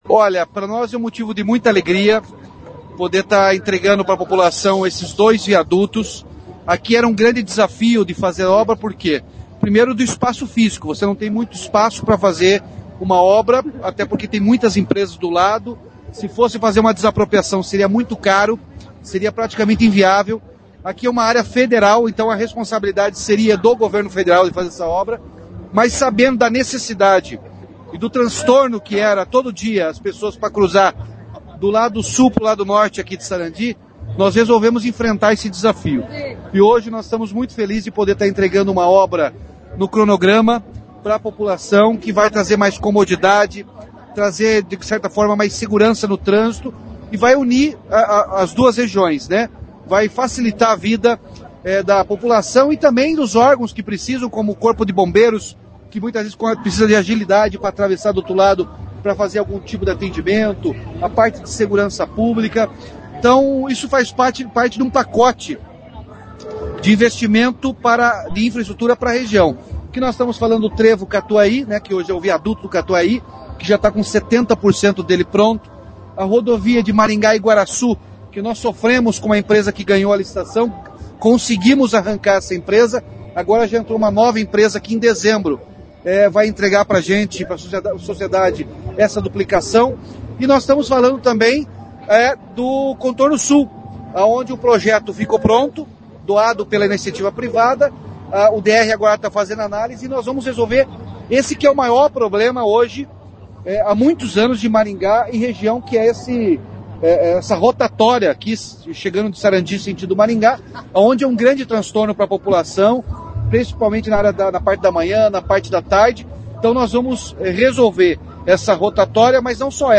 Em entrevista coletiva o governador reforçou a explicação. Este tipo de projeto evita desapropriação Ouça: